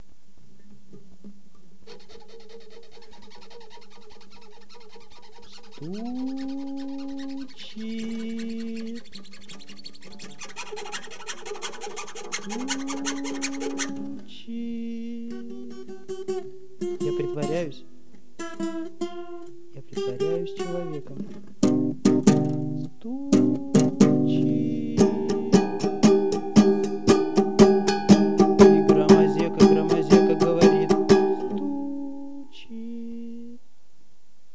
Мандолина и лежащий человек.